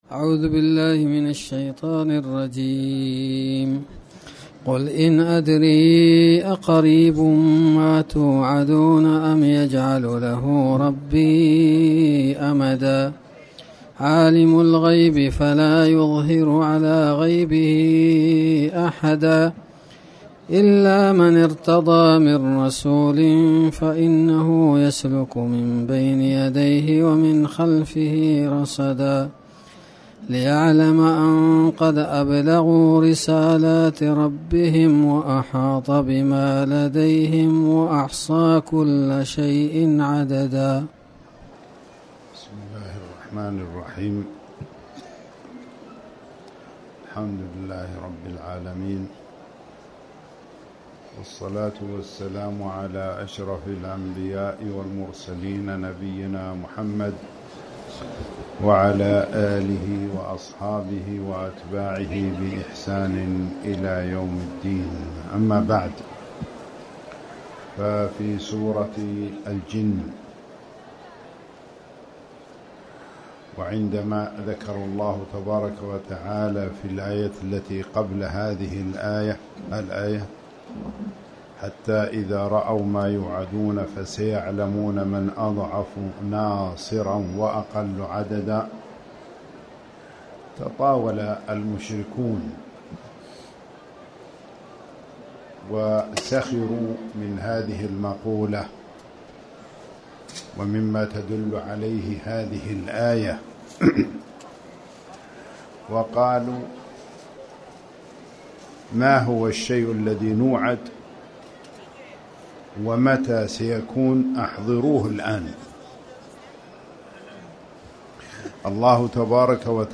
تاريخ النشر ٢١ شعبان ١٤٣٩ هـ المكان: المسجد الحرام الشيخ